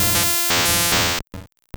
Cri d'Élektek dans Pokémon Or et Argent.